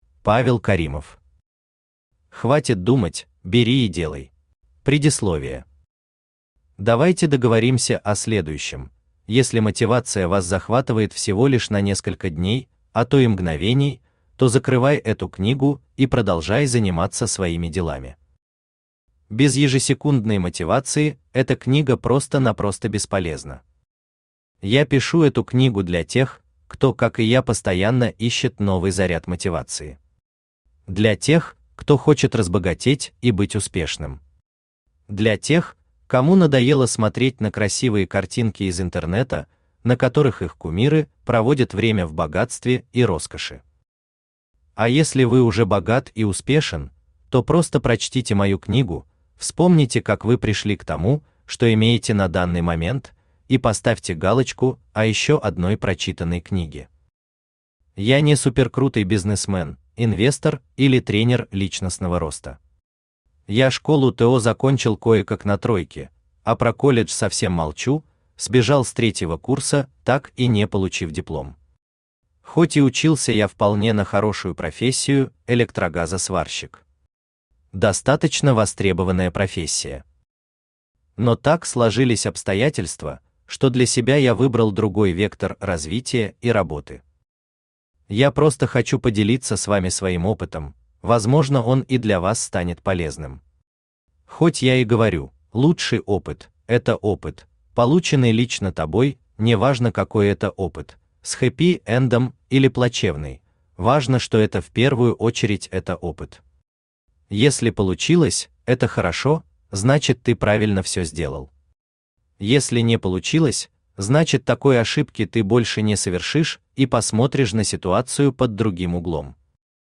Аудиокнига Хватит думать, бери и делай | Библиотека аудиокниг
Aудиокнига Хватит думать, бери и делай Автор Павел Магсумович Каримов Читает аудиокнигу Авточтец ЛитРес.